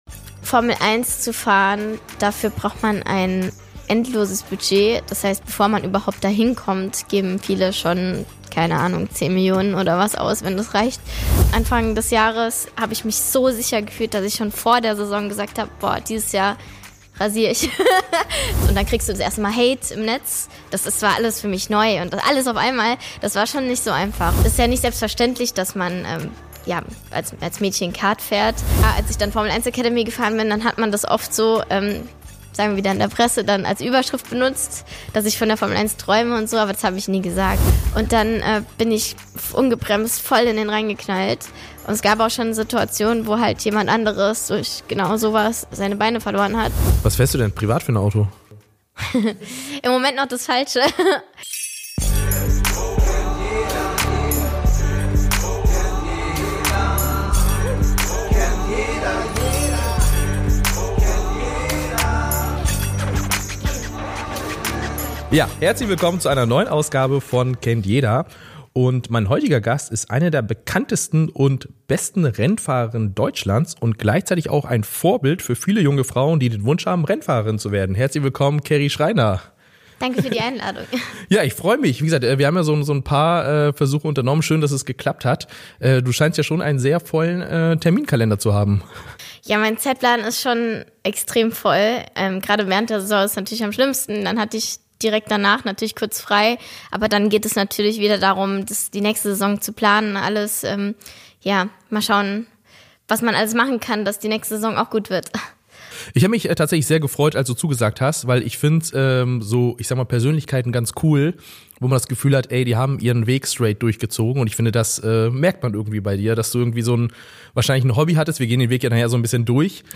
Beschreibung vor 3 Monaten In der neuen Folge von „Kennt jeder“ geht es um Geschwindigkeit, Durchhaltevermögen und den Kampf um Anerkennung im Motorsport: Carrie Schreiner ist zu Gast im Podcast. Carrie spricht offen über ihren Weg in den Motorsport – von den ersten Schritten im Kartsport bis hin zur Formel 1 Academy.